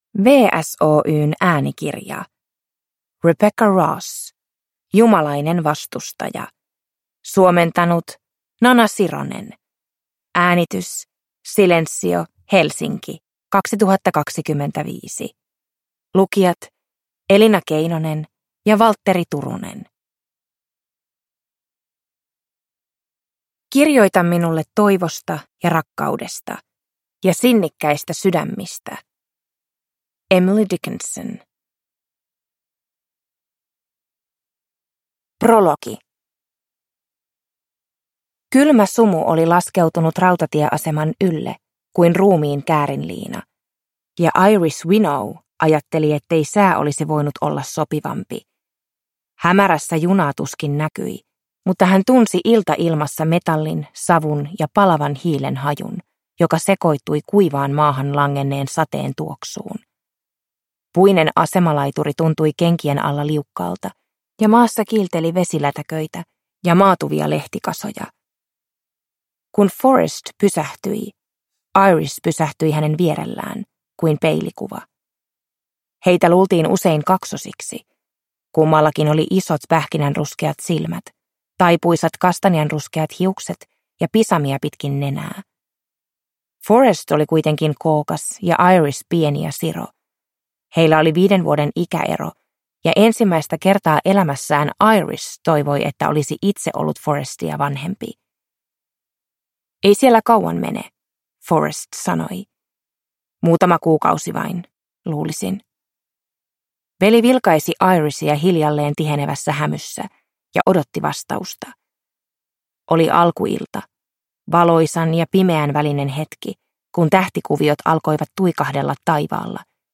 Jumalainen vastustaja – Ljudbok